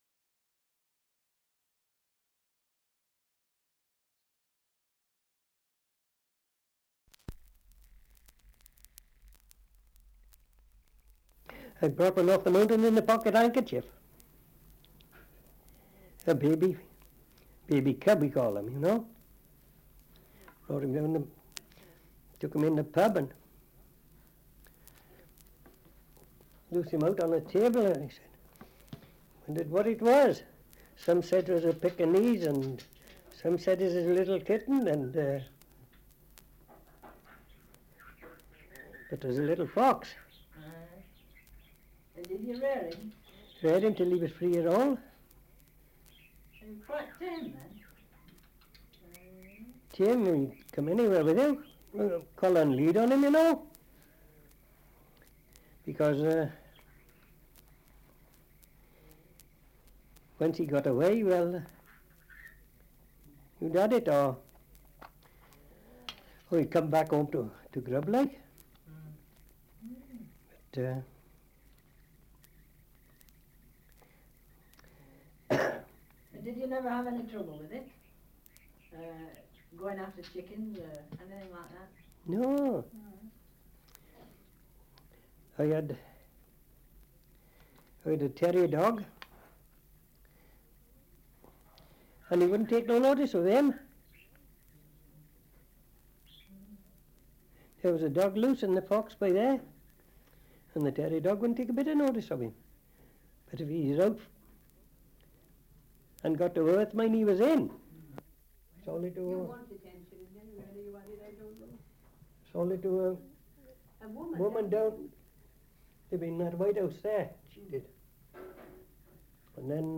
Survey of English Dialects recording in Llanellen, Monmouthshire
78 r.p.m., cellulose nitrate on aluminium